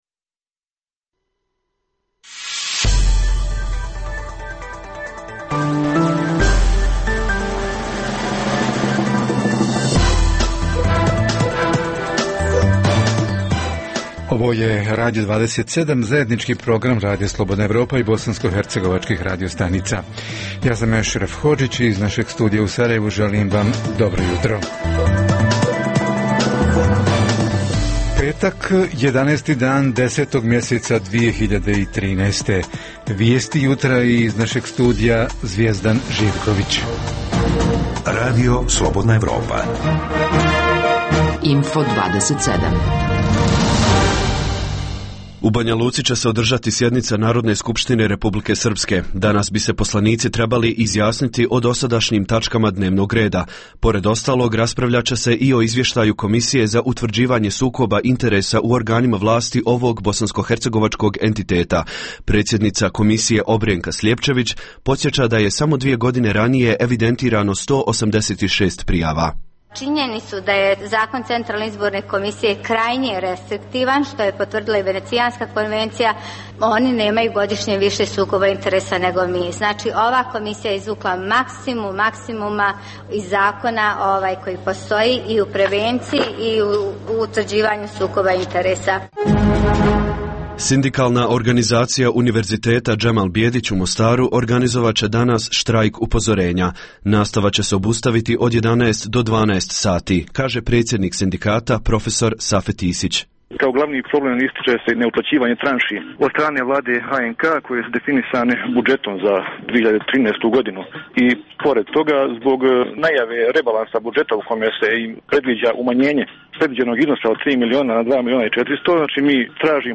O tome izvještaji i tonske bilješke naših reportera, - Redovna rubrika „Za zdrav život“ i – šta su dobre, a šta loše strane antibiotika?